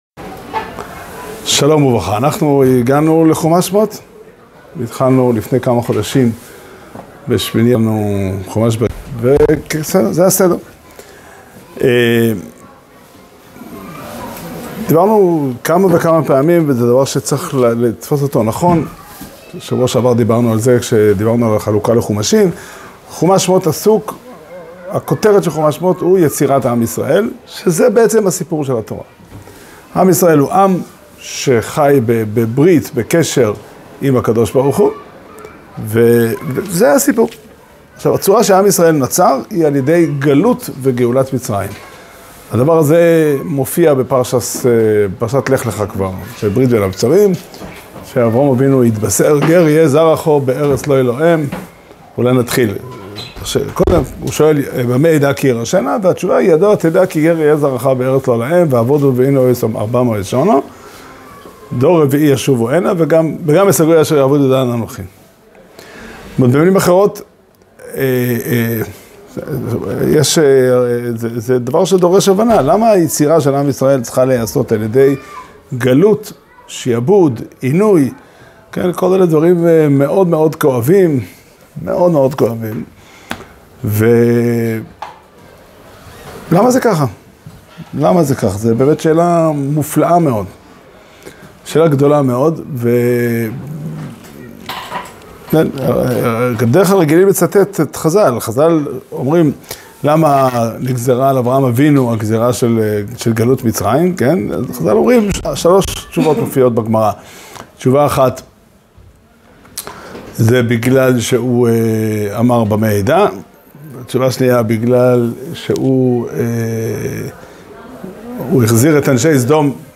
שיעור שנמסר בבית המדרש פתחי עולם בתאריך י"ג טבת תשפ"ה